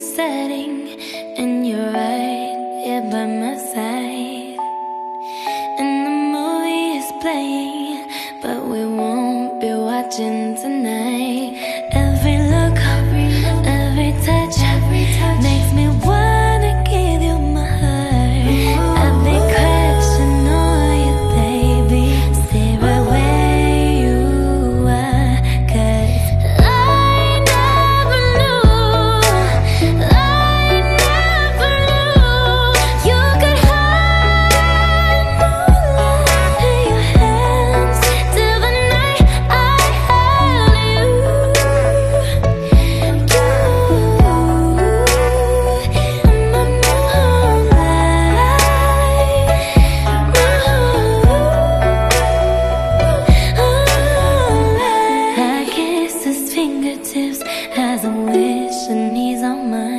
Música, ambiente y energía al máximo.